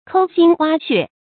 摳心挖血 注音： ㄎㄡ ㄒㄧㄣ ㄨㄚ ㄒㄩㄝˋ 讀音讀法： 意思解釋： 猶言摳心挖肚。